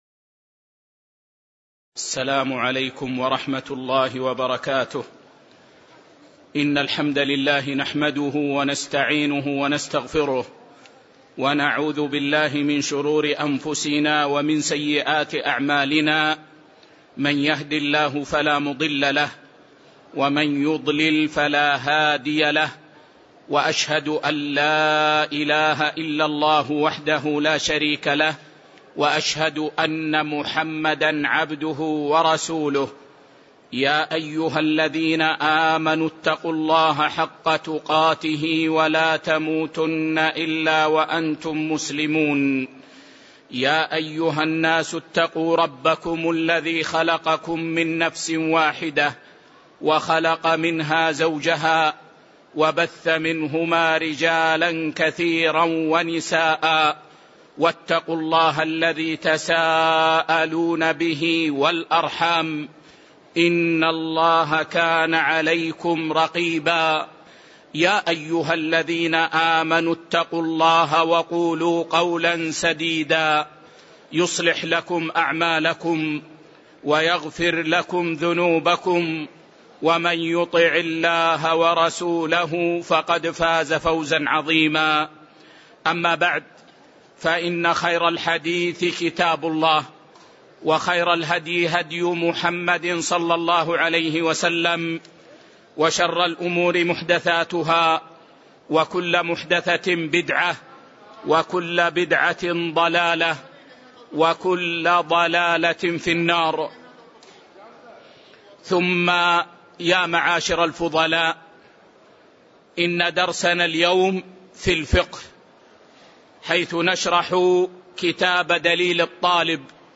تاريخ النشر ١٠ ربيع الأول ١٤٤١ هـ المكان: المسجد النبوي الشيخ